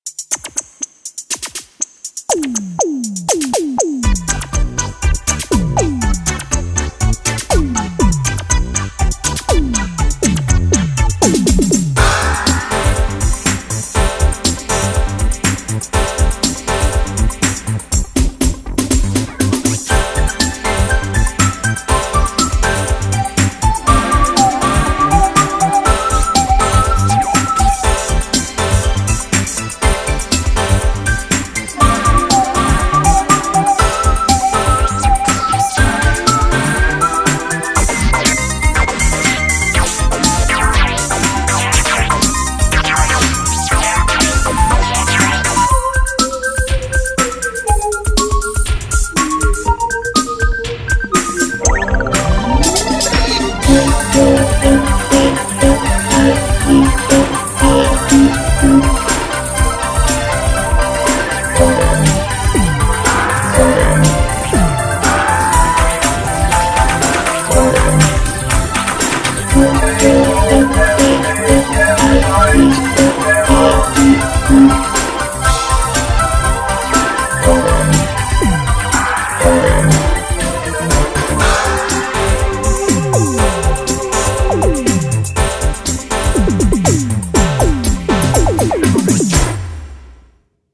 BPM121
A nice and robotic techno pop track!